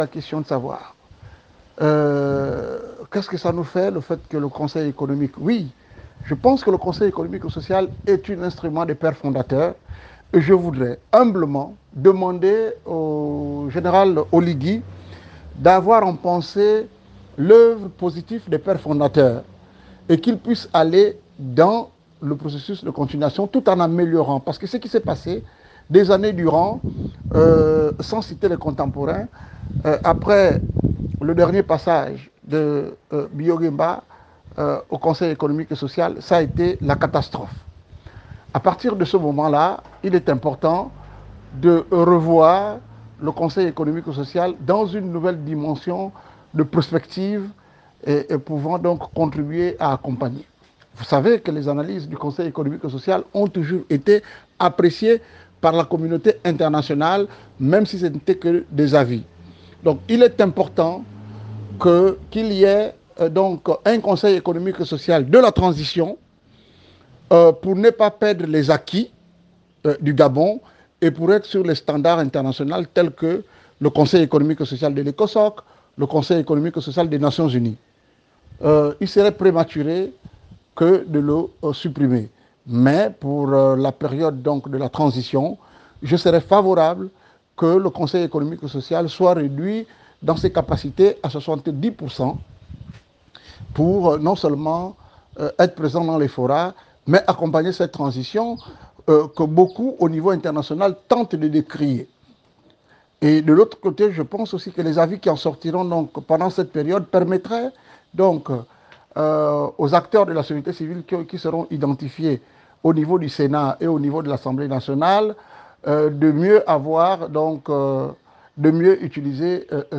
Soutien à la Transition, vigilance, inclusivité, avenir du Conseil économique social et environnemental (CESE), classe politique, falsification de la signature du Chef de l’État, Nicaise Moulombi second Vice-président de l’institution citée plus haut, donne son point de vue au sujet de la Transition. Sans filtre, l’entièreté de notre entretien.